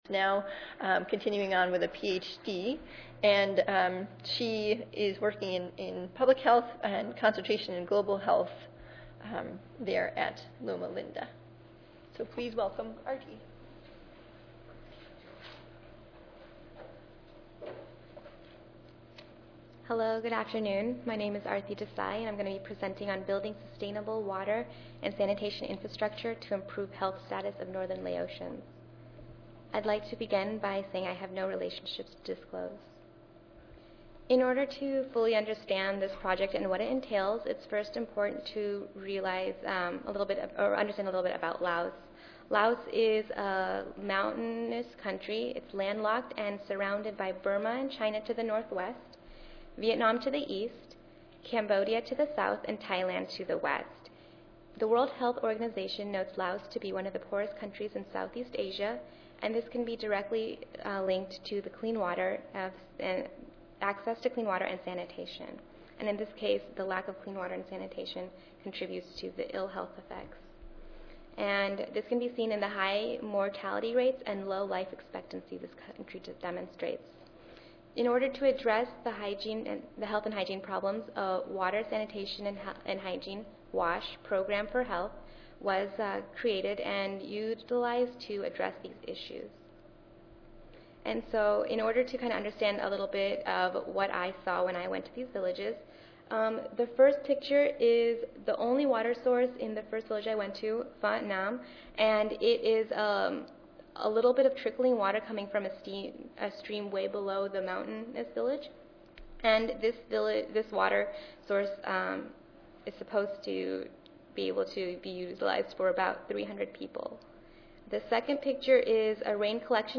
141st APHA Annual Meeting and Exposition
Recorded Annual Meeting Presentations (RAMP) are now available for purchase.